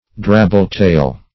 Drabble-tail \Drab"ble-tail`\, n. A draggle-tail; a slattern.